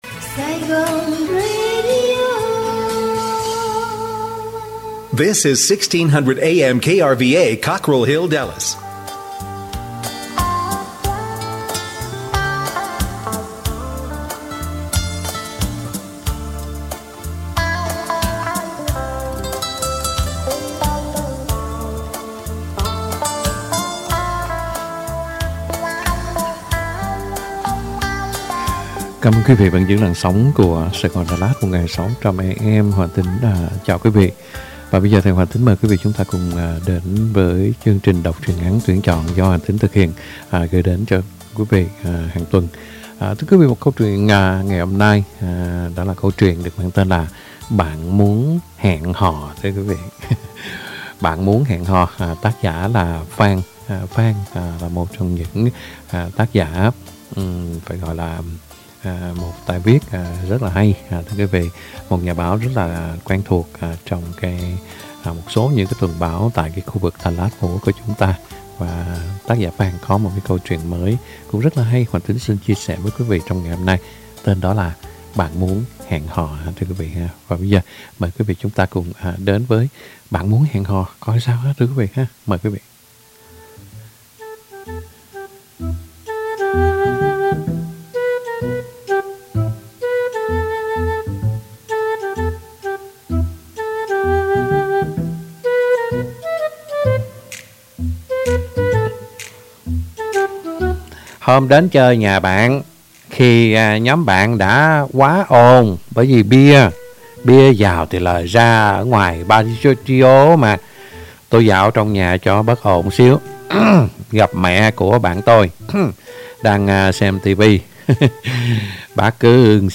Đọc truyện ngắn = Bạn Muốn Hẹn Hò … 05/31/2022 .